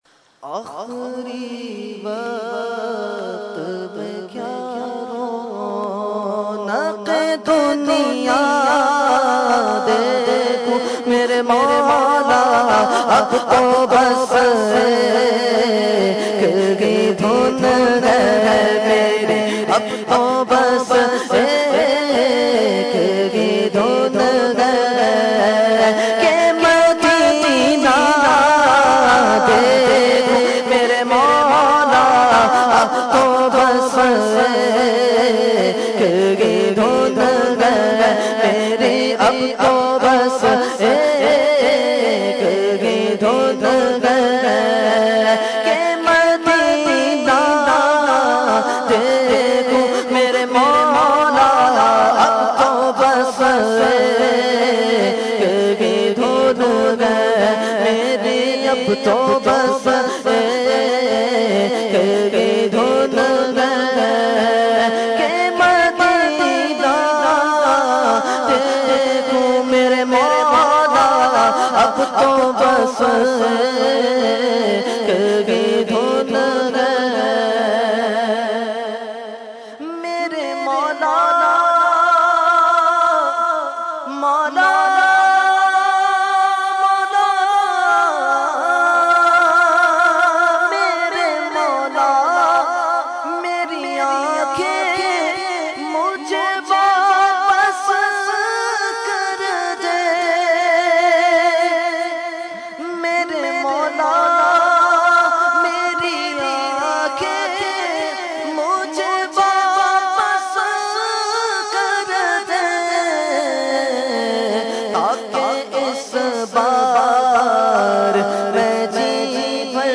Category : Naat | Language : UrduEvent : Urs Ashraful Mashaikh 2014